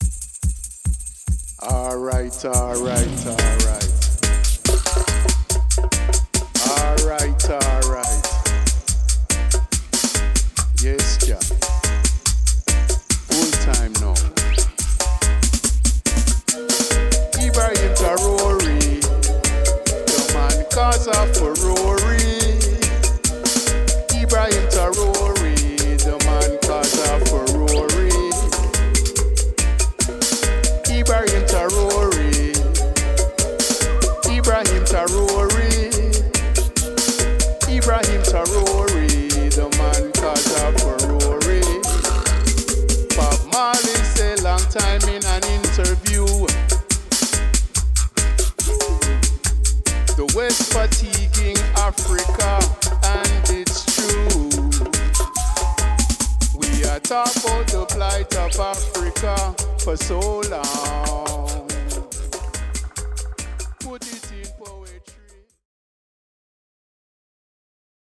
percussion
Violin
vocals
Real Dub Roots Reggae